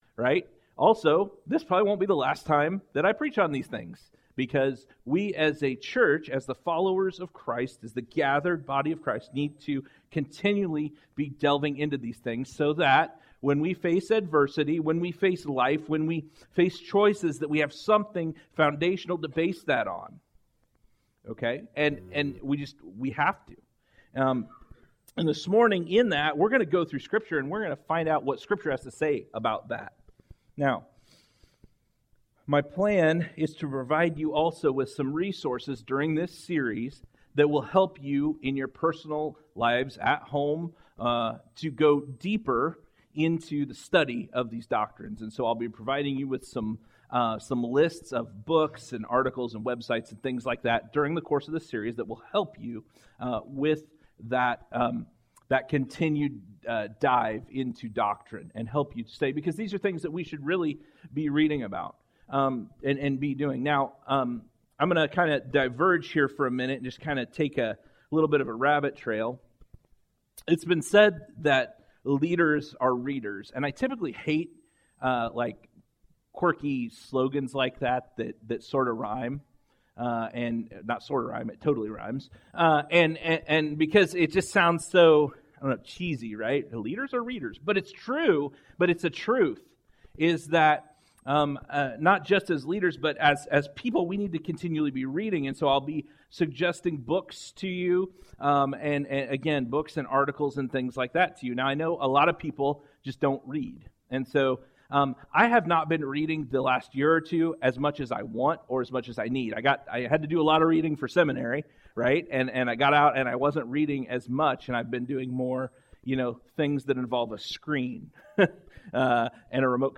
Weekly messages/sermons from GFC Ashton.